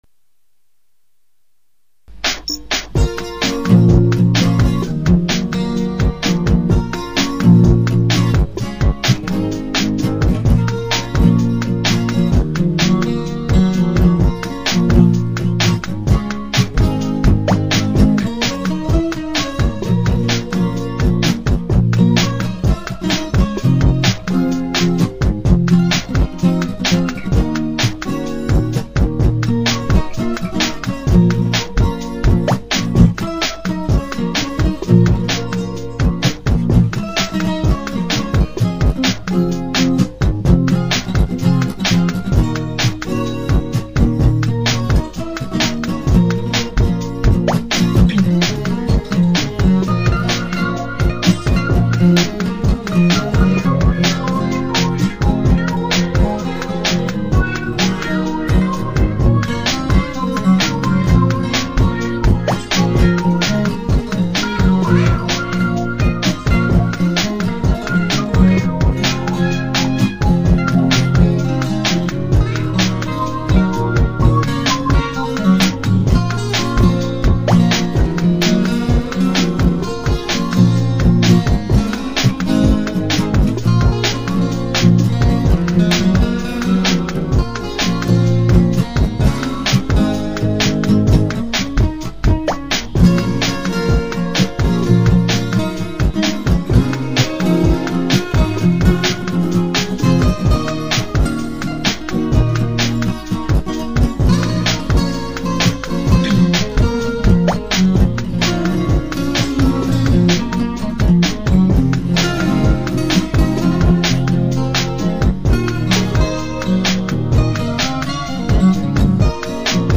Rock & Roll
Prog rock
Rhythm & Blues